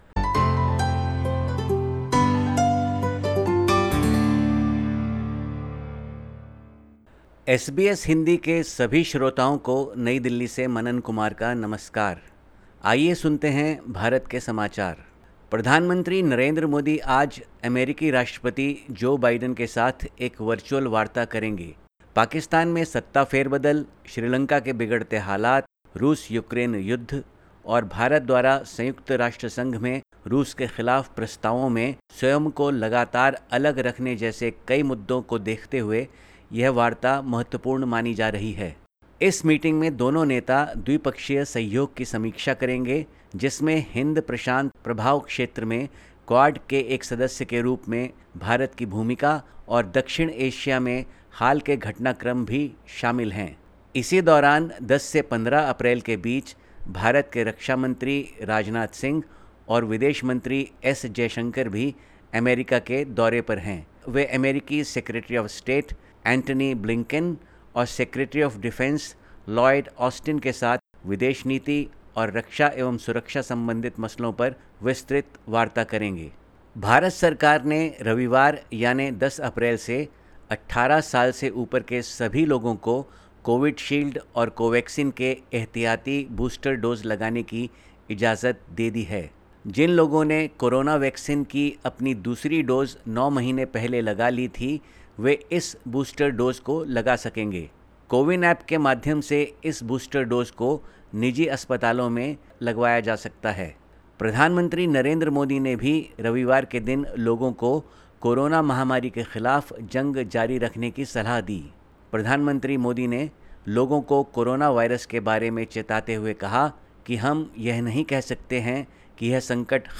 Listen to the latest SBS Hindi report from India. 11/04/2022